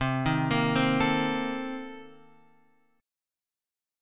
C Minor 13th